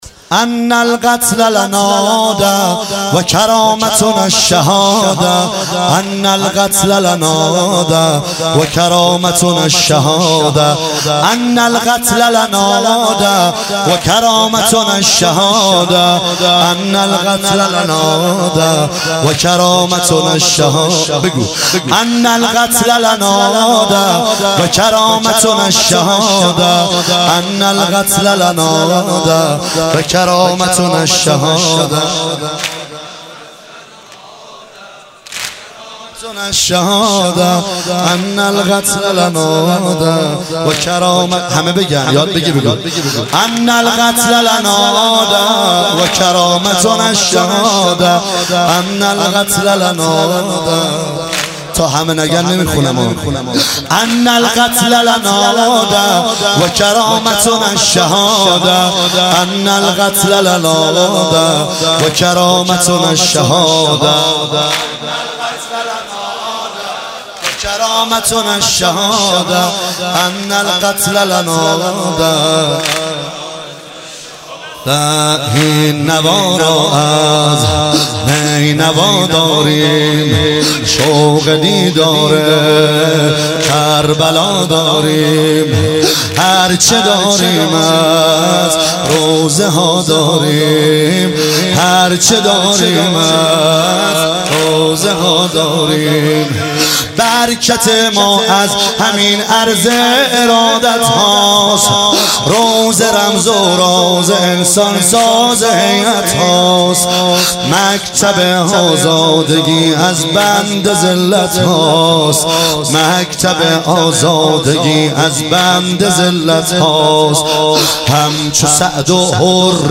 شب هشتم محرم 96 - واحد عربی